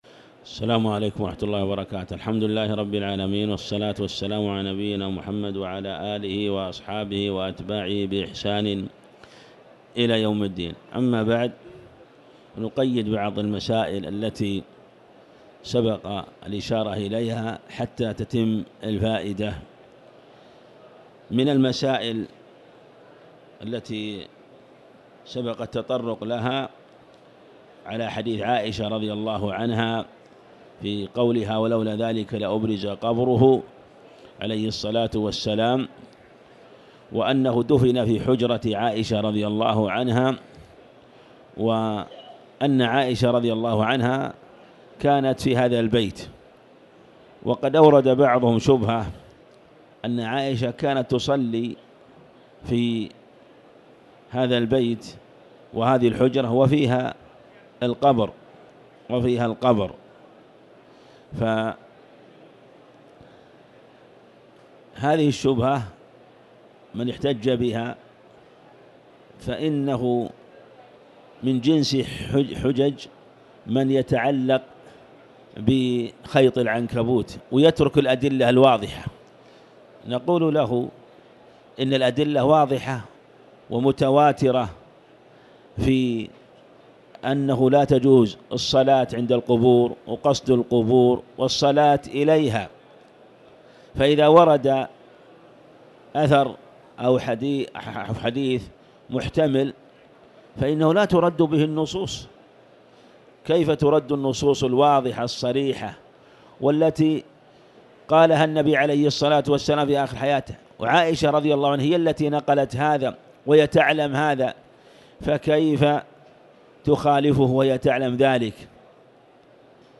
تاريخ النشر ٢٦ رمضان ١٤٤٠ هـ المكان: المسجد الحرام الشيخ